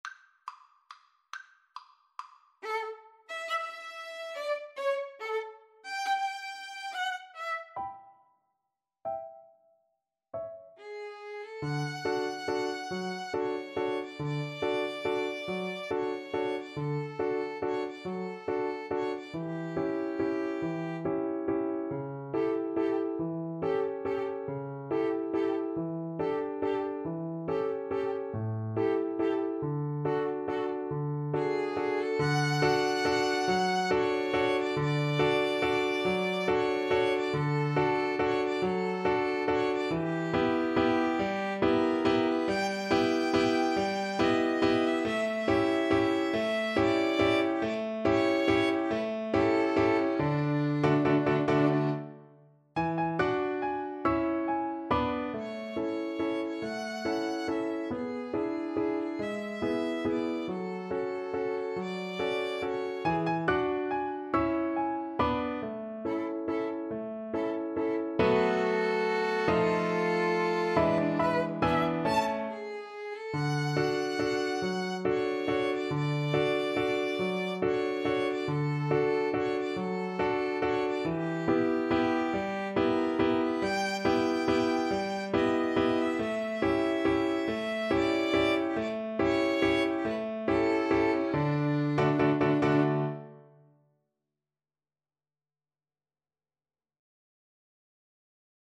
~ = 140 Tempo di Valse
3/4 (View more 3/4 Music)
Piano Trio  (View more Intermediate Piano Trio Music)